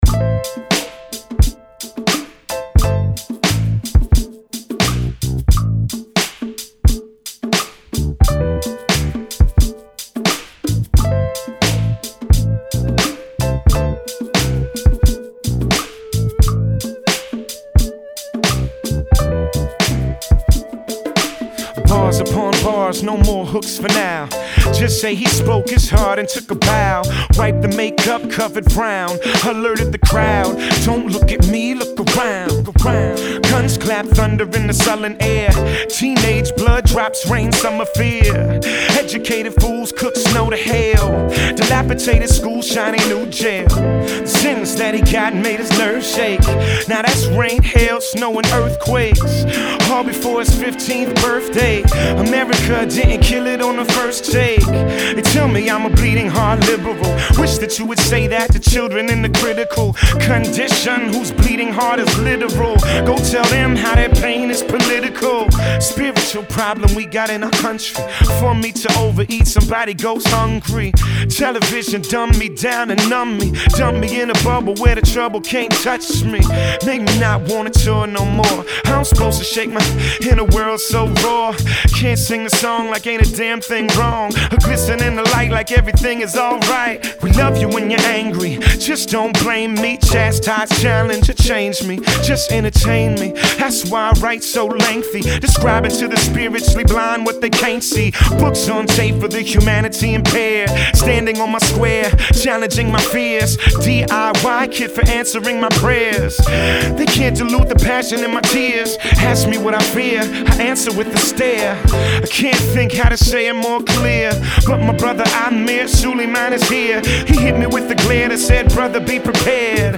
rap
afrocentric beat